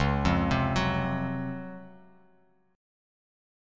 DbM11 Chord
Listen to DbM11 strummed